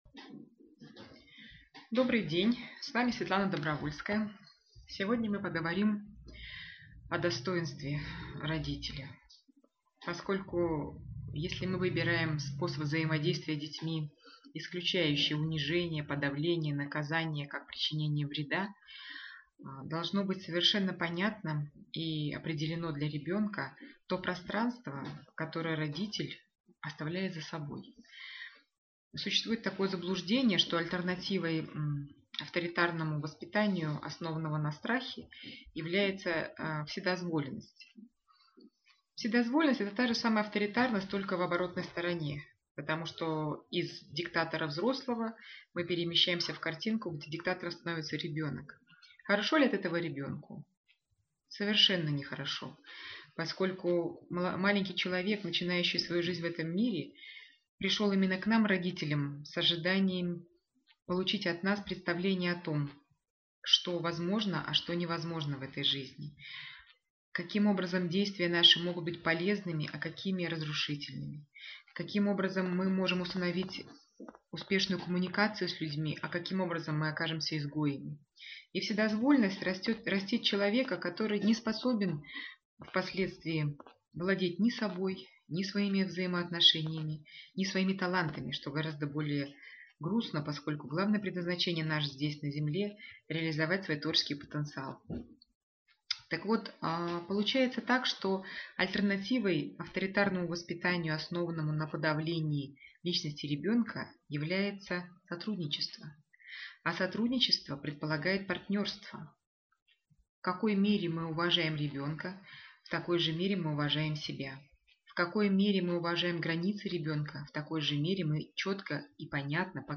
Семинар: "О достоинстве родителей" | Обретение силы Любви